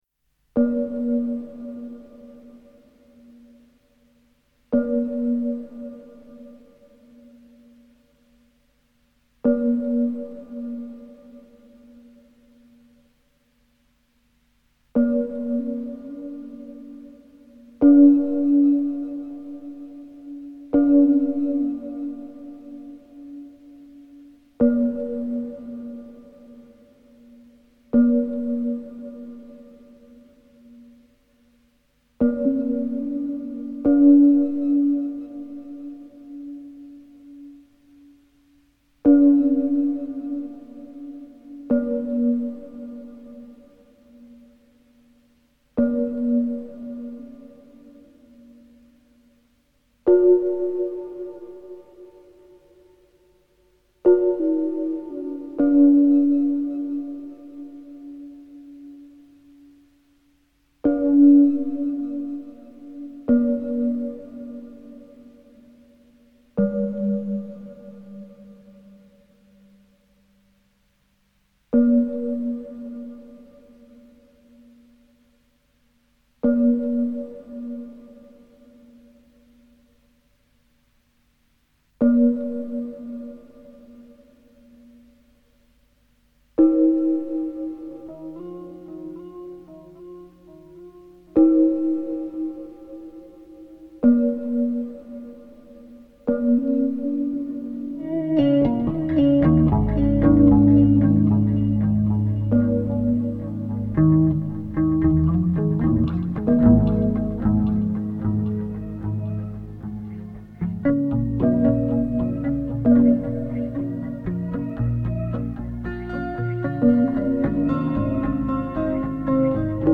muzyka, instrumenty
teksty, głosy